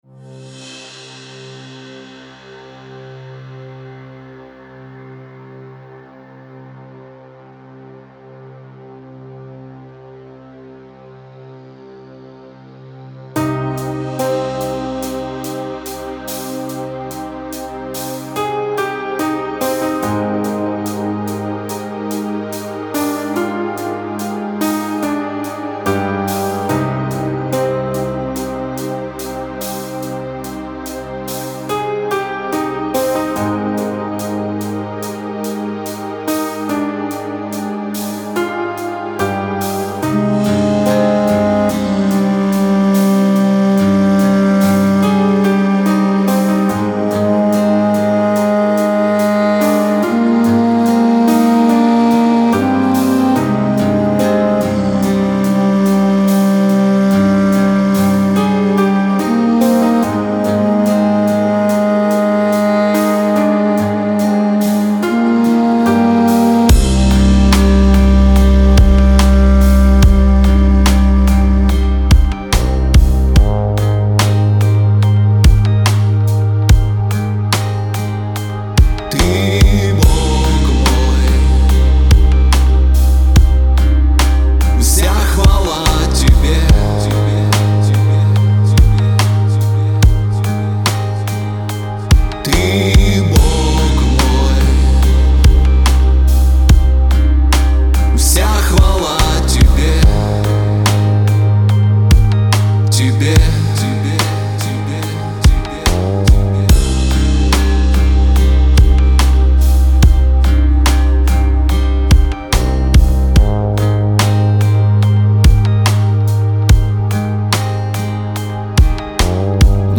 BPM: 72